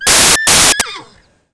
Mercury reprennant souffle 10 minutes après sa naissance...
petit_heni_poulinou.wav